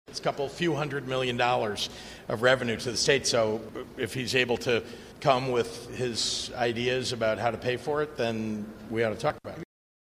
Governor J.B. Pritzker said any change to the estate tax would create a hole in the state budget.